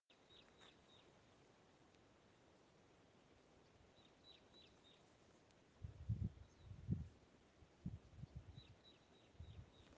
клест-еловик, Loxia curvirostra
Administratīvā teritorijaLubānas novads
Skaits5 - 7
СтатусПоёт